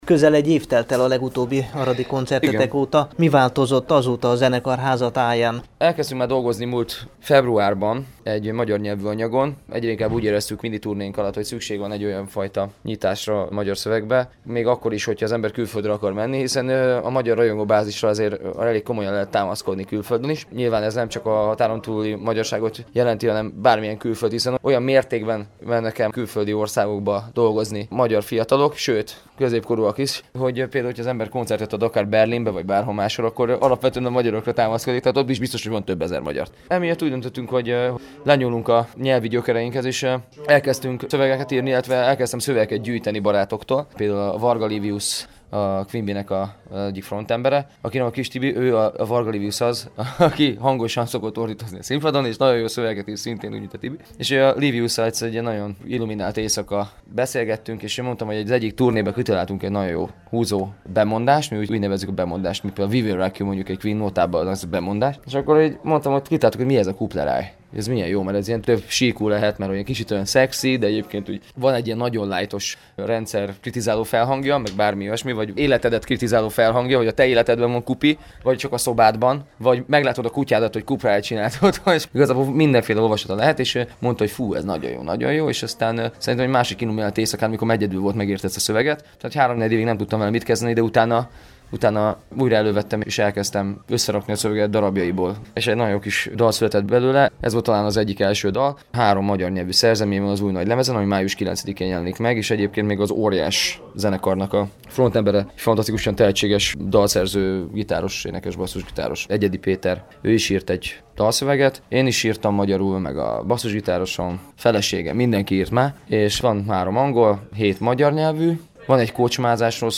Az interjú Temesvári Rádió ifjúsági műsora számára készült.
B_The_First-koncert_Aradon.mp3